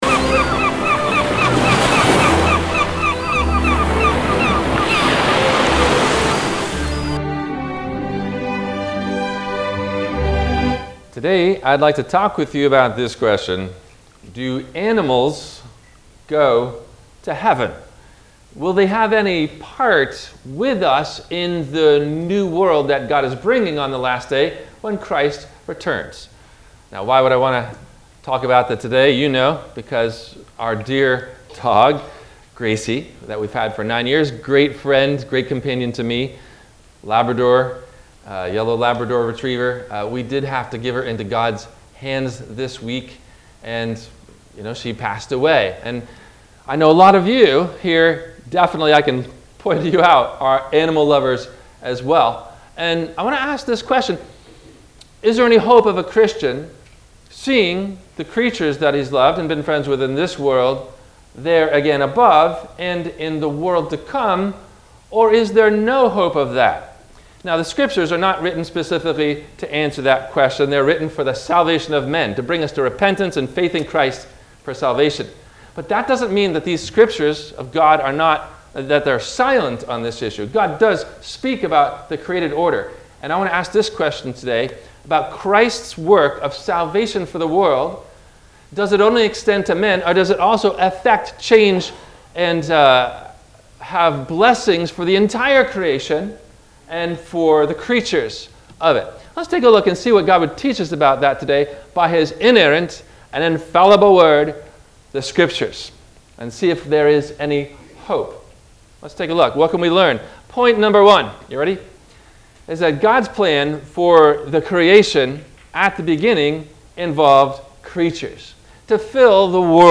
Do Our Animals Go To Heaven? – WMIE Radio Sermon – September 05 2022